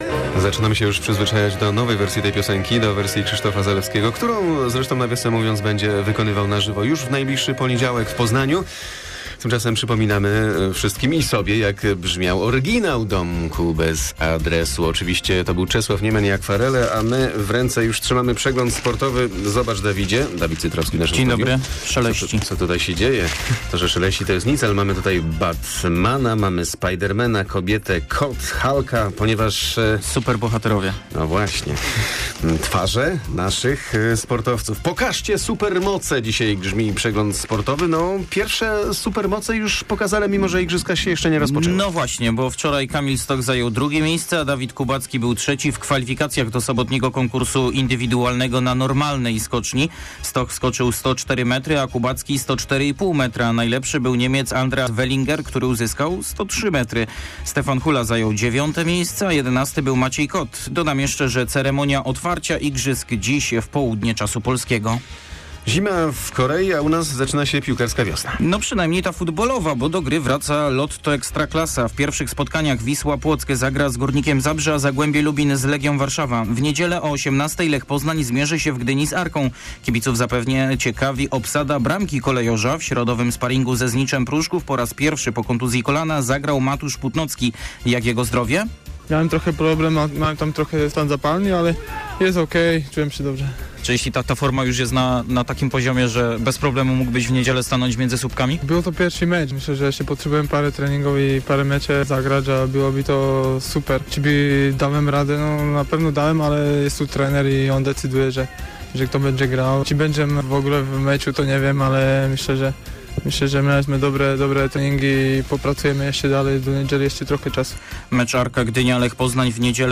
09.02 serwis sportowy godz. 7:45